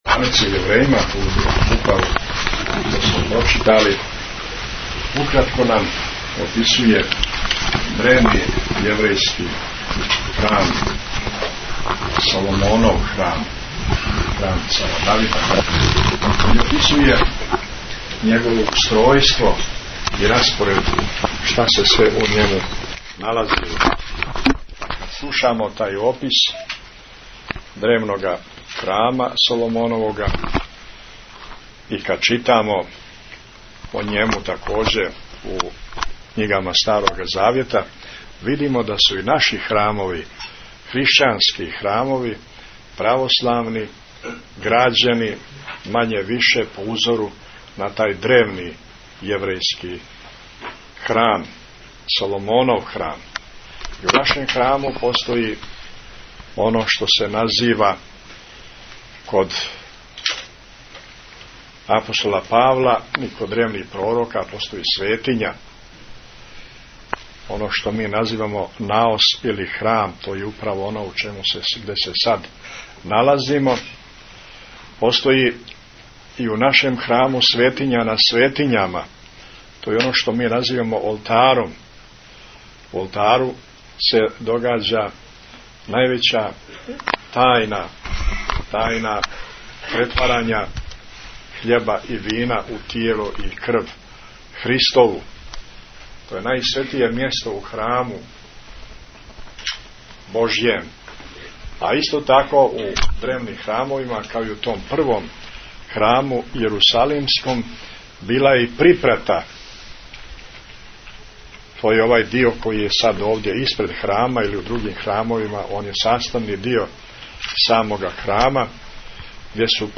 Tagged: Бесједе Наслов: AEM Crnogorsko-primorski Amfilohije Албум: Besjede Година: 2009 Величина: 12:18 минута (2.11 МБ) Формат: MP3 Mono 22kHz 24Kbps (CBR) Бесједа Његовог Високопреосвештентсва Архиепископа Цетињског Митрополита Црногорско - приморског Г. Амфилохија чувара Светосавског трона са Свете Архијерејске Литургије коју је на празник Ваведења Пресвете Богородице служио у манастиру Ћелија Добрска код Цетиња поводом храмовске славе манастира.